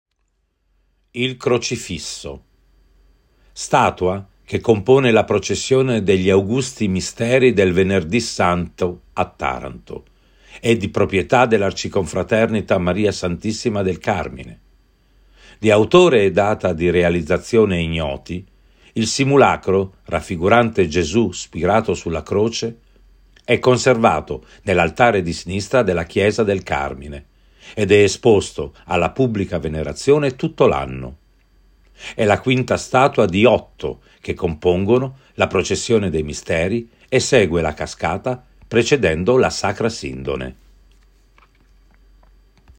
Audioguida